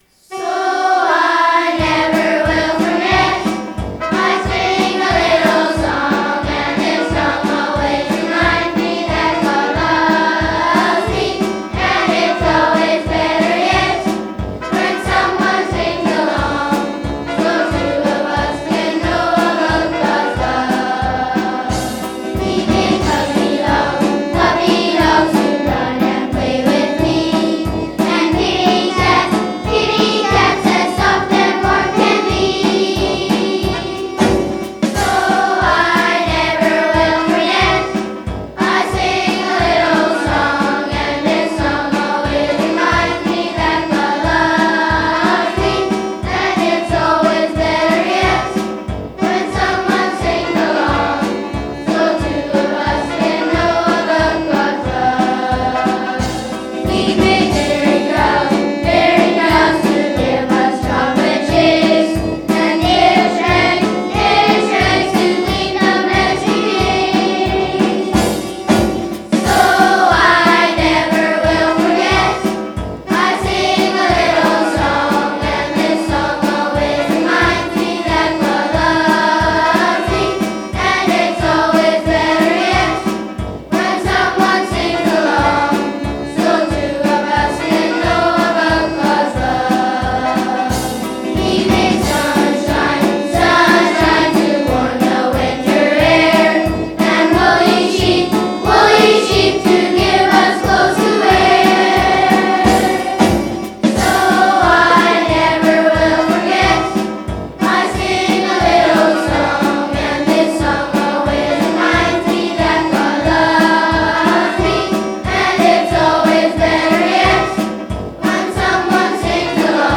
It's all gospel songs sung by gospel children. The backing band is a Casio of the oom-pah variety and the children singing are only mildly annoying to listen to.